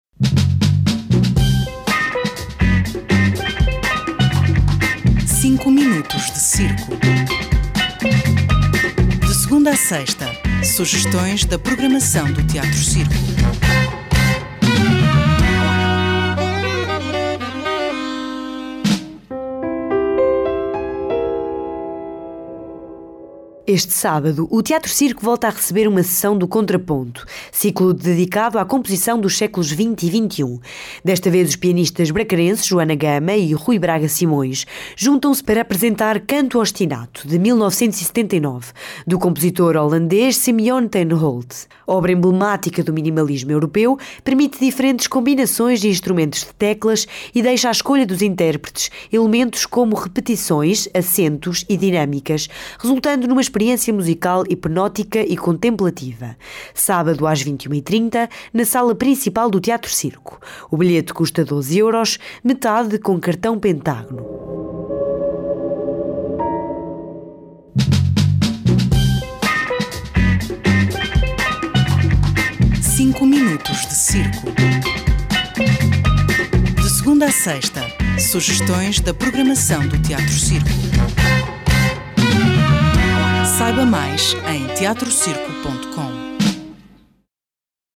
Obra emblemática do minimalismo europeu, permite diferentes combinações de instrumentos de teclas e deixa à escolha dos intérpretes elementos como repetições, acentos e dinâmicas, resultando numa experiência musical hipnótica e contemplativa.